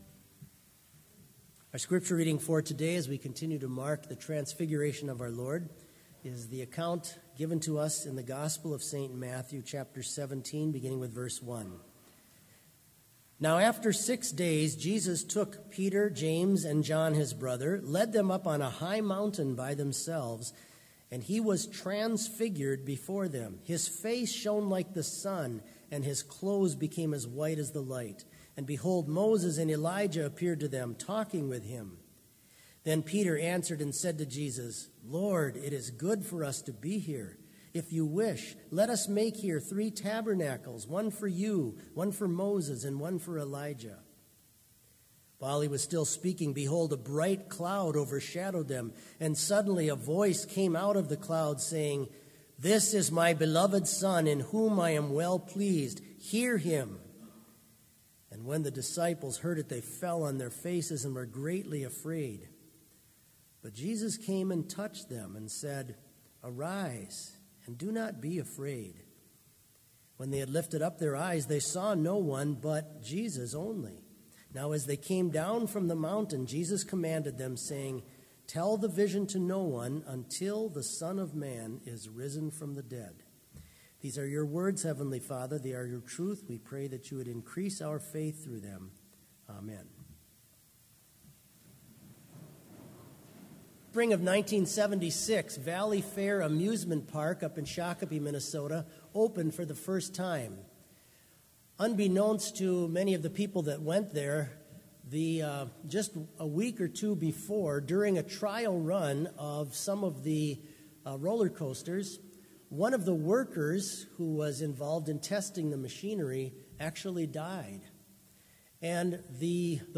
Complete service audio for Chapel - February 12, 2019